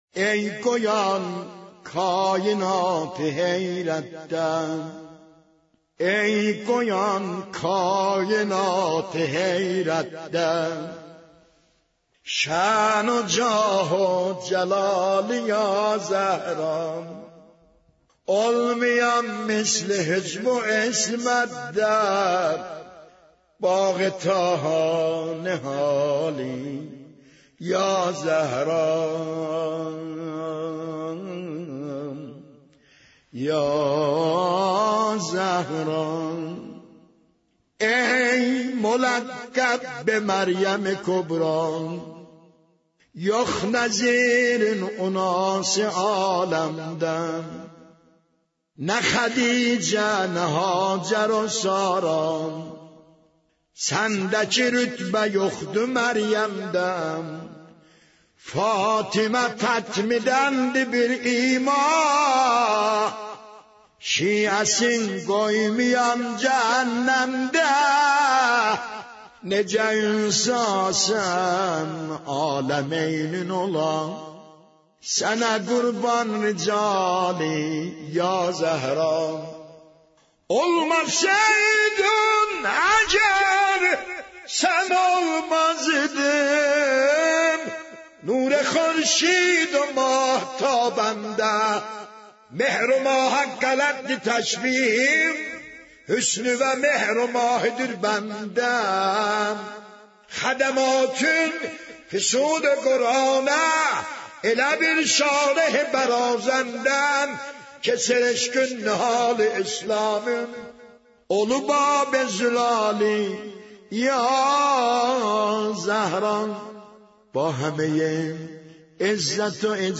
مداحی و نوحه